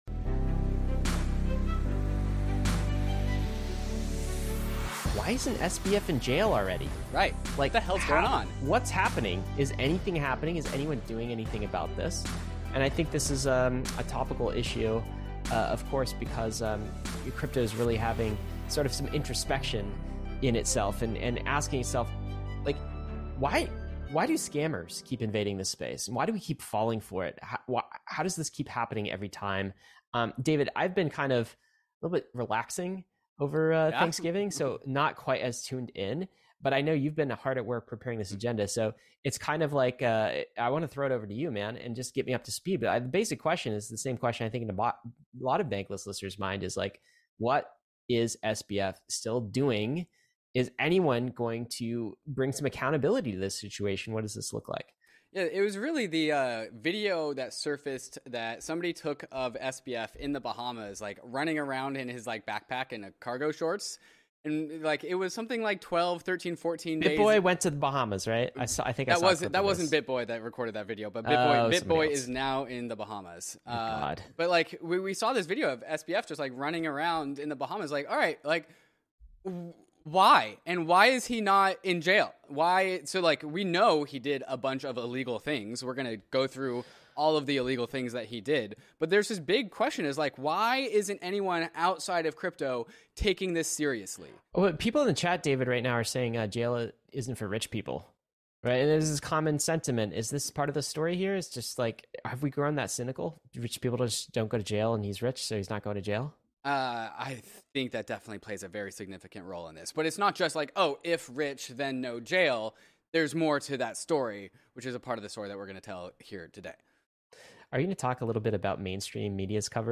Also, stay tuned toward the end of the show for a special guest appearance from our boots-on-the-ground Bahamas correspondent to give their take from the belly of the beast.